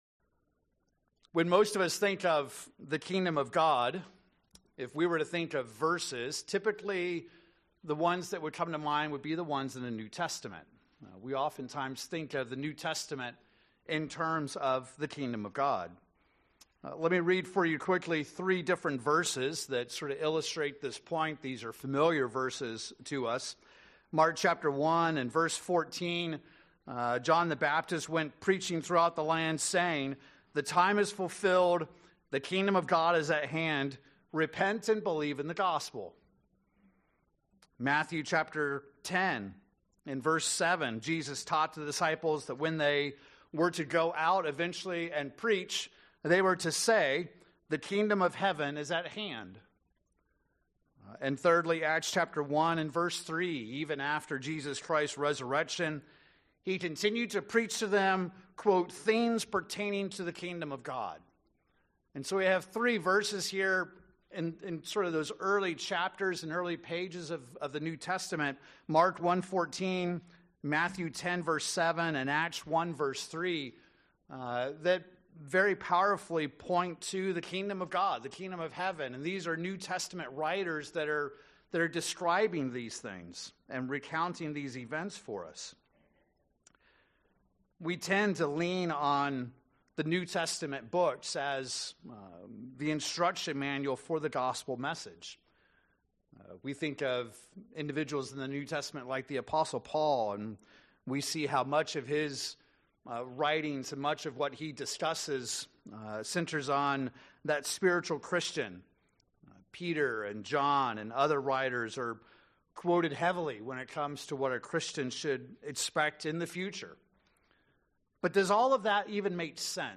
When most think of the gospel message, verses likely come to mind in the New Testament. In this sermon we examine the importance of the Old Testament as the foundation for what we learn in the New Testament.